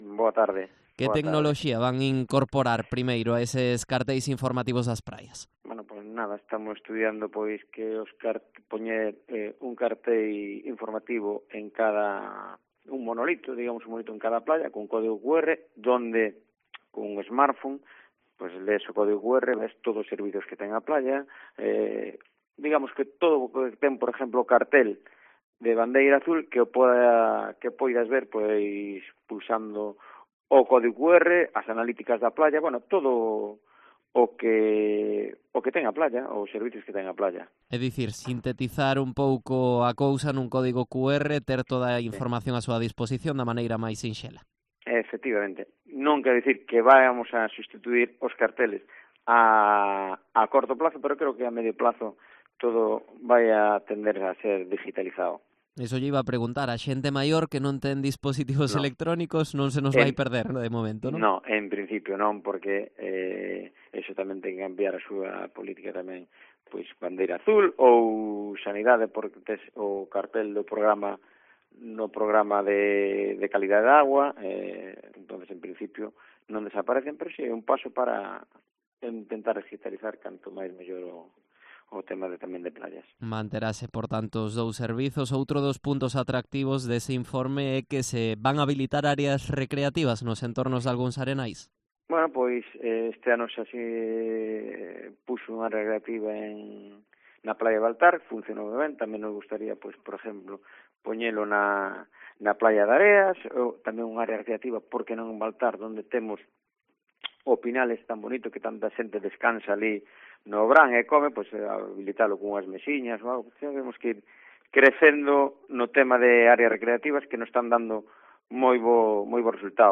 Entrevista a Juan Deza, concejal de Medio Ambiente de Sanxenxo